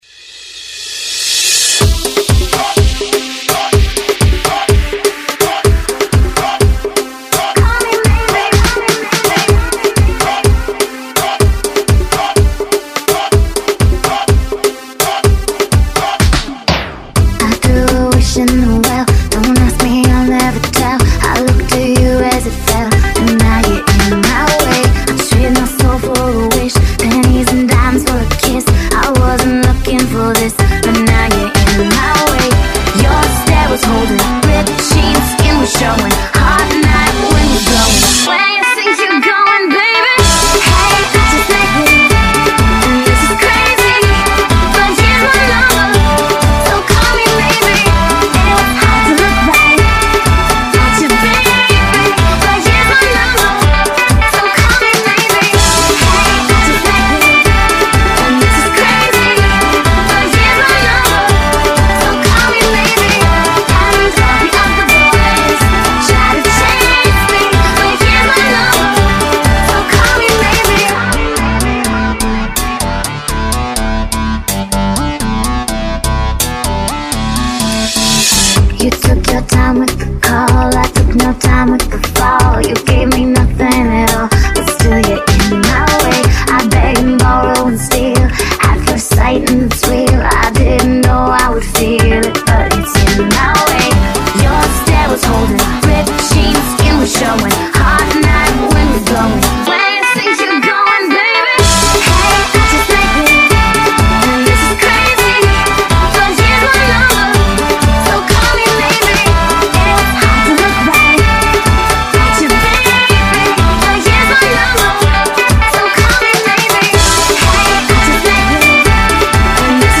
very catchy Afrobeat remix